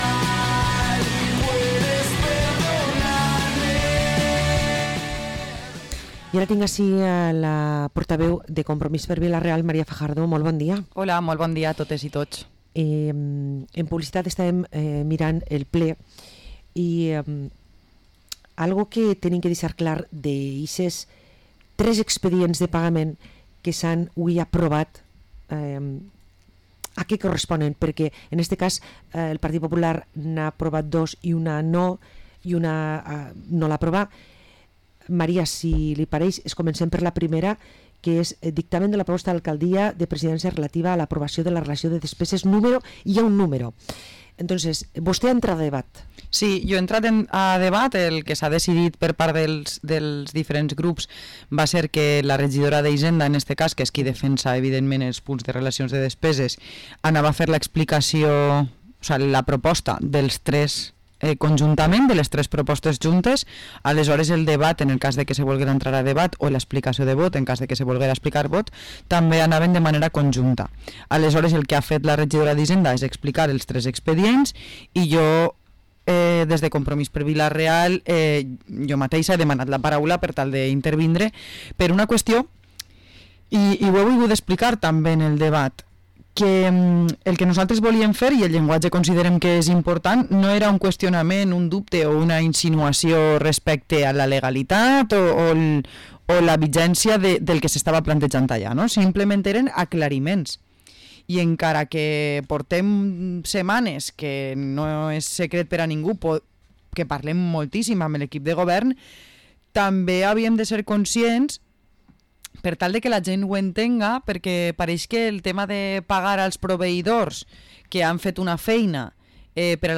Parlem amb la portaveu i regidora de Compromis per Vila-real, María Fajardo